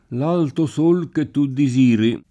desirare v.; desiro [de@&ro] — anche disirare: disiro [di@&ro] — es.: l’alto Sol che tu disiri [